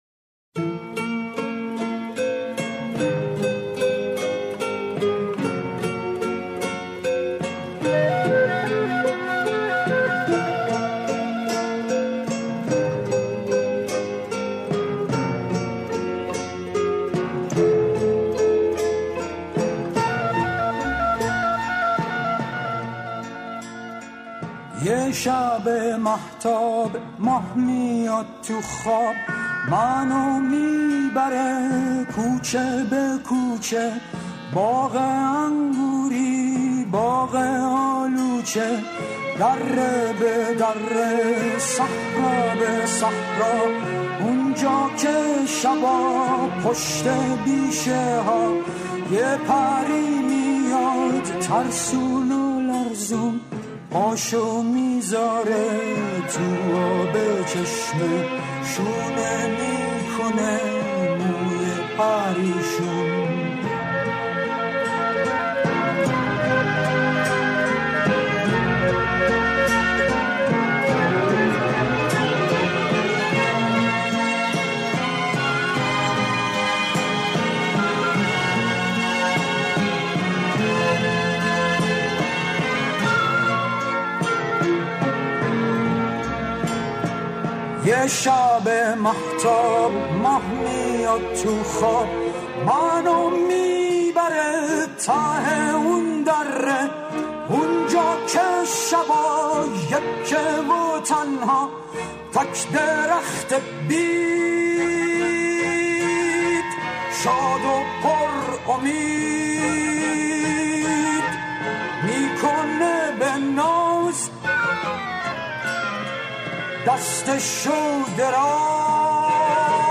اهنگ غمگین